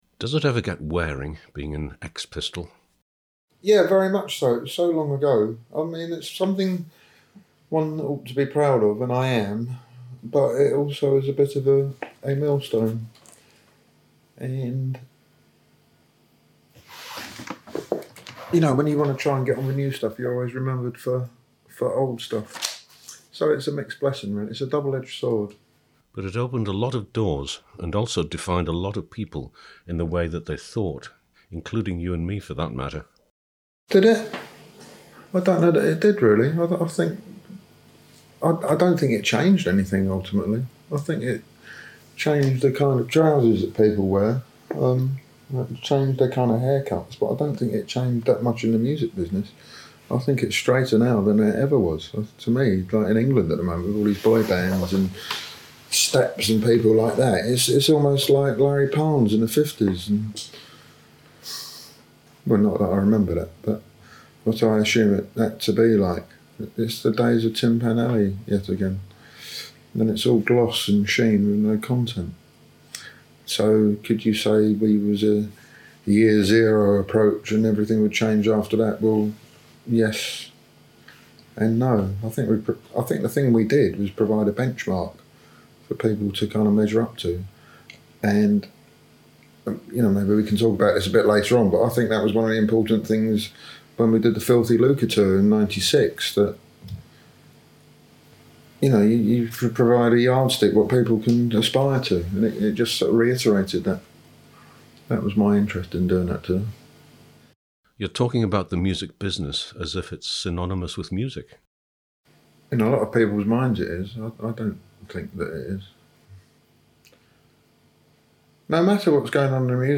Mike Thorne interviewed ex Sex Pistol Glen Matlock at his home in London on June 29 2000: a lively and frank interchange
Glen Matlock in interview